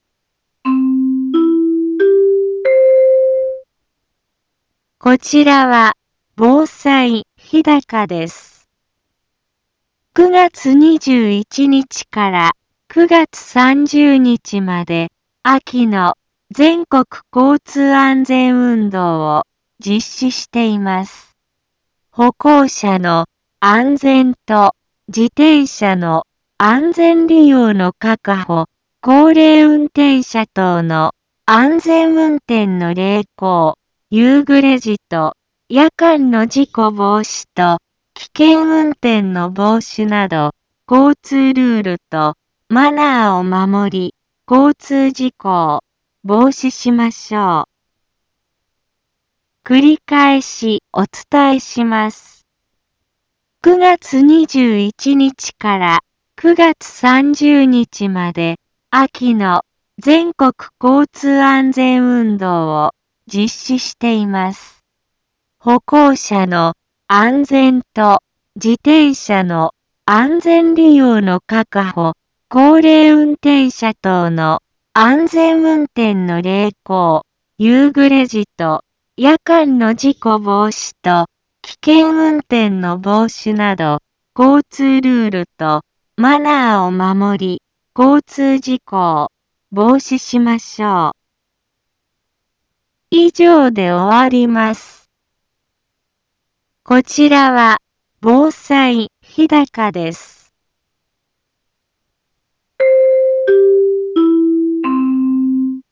一般放送情報
Back Home 一般放送情報 音声放送 再生 一般放送情報 登録日時：2020-09-21 15:03:44 タイトル：交通安全のお知らせ インフォメーション：こちらは、防災ひだかです。